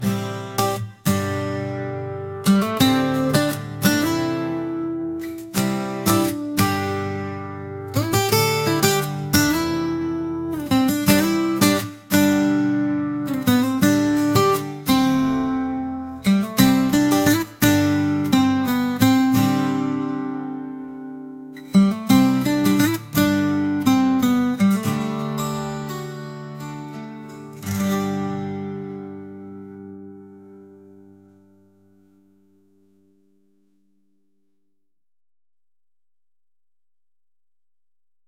「大人な雰囲気」